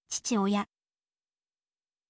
chichi oya